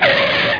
SKID.mp3